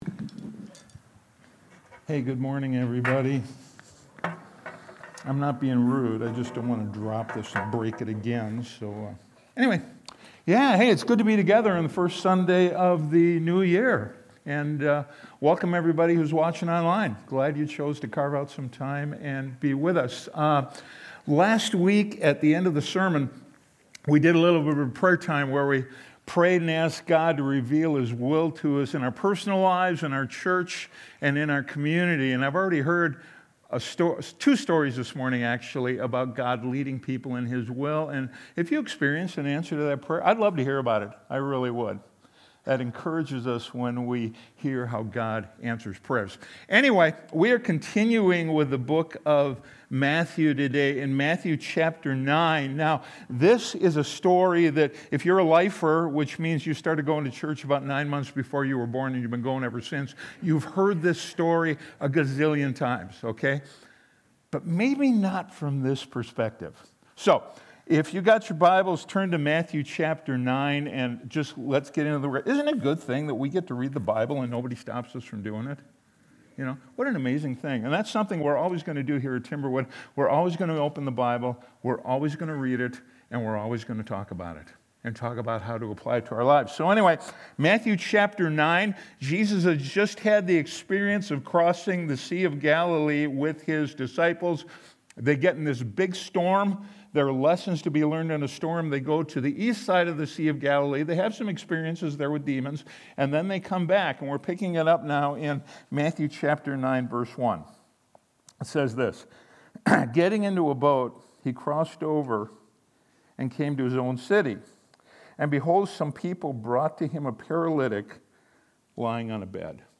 Sunday Sermon: 1-4-26